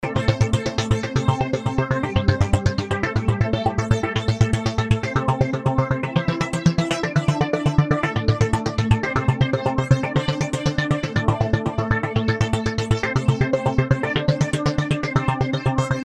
绳子绑在港口的船上
描述：当绳索随着波浪进出而拉紧绳索时，绳索在港口拉动船/船/驳船的声音。模拟不同的尺寸。 装备：在演播室环境中使用SONY PCMM10录制，使用吱吱作响的办公椅重现绳索的声音。 处理：降噪，EQ te减少椅子上的特定谐波，使其听起来很金属。